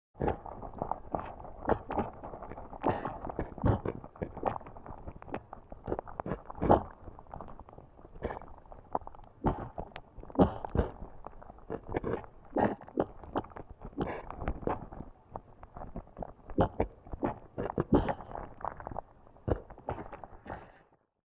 Free SFX sound effect: Lava Churn.
Lava Churn
yt_o9QiNgnowSw_lava_churn.mp3